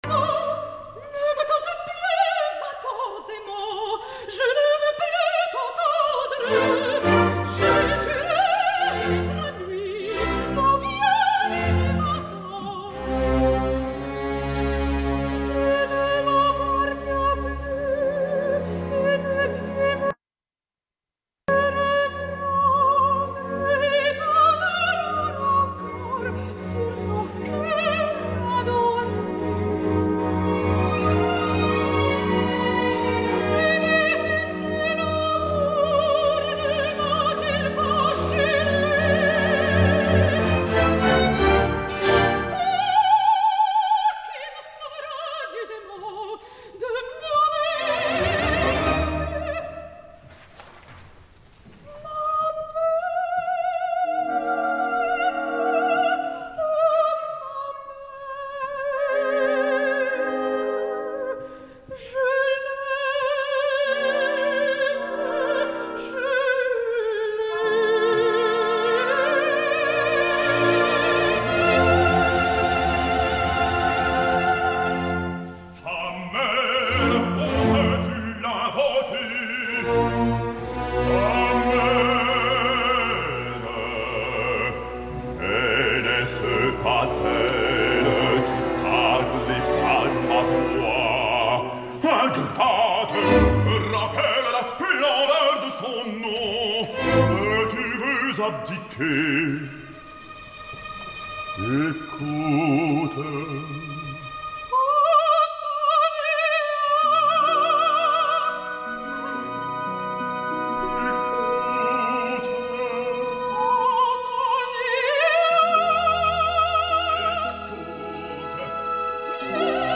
I per acabar aquest tast escoltem a Sonya Yoncheva, Laurent Naouri i Sylvie Brunet en el famós tercet d’Antonia, Miracle i la mare d’Antonia
Versió de concert
Sonya Yoncheva, soprano (Olympia/Antonia/Giulietta/Stella)
Laurent Naouri, baryton (Lindorf/Coppelius/Miracle/Dapertutto)
Sylvie Brunet, mezzo-soprano (La voix de la Mère)
22 de novembre de 2012, Salle Pleyel de Paris